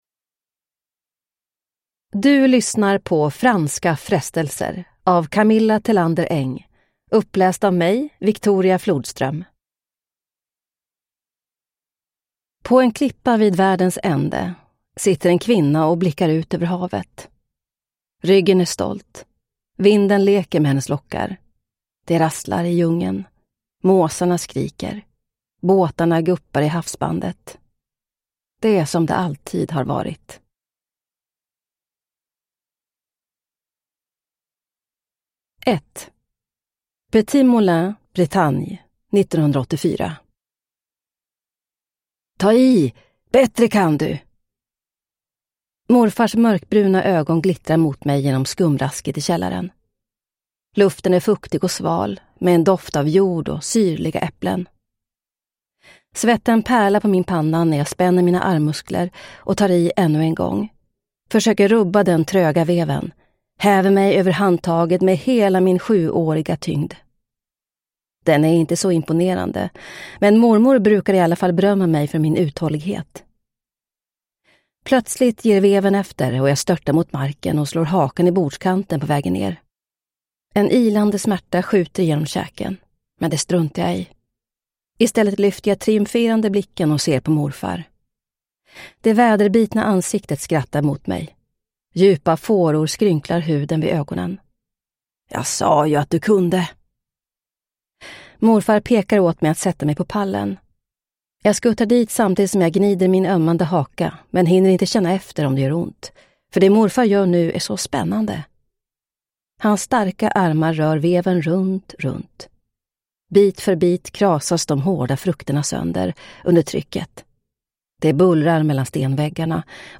Franska frestelser – Ljudbok – Laddas ner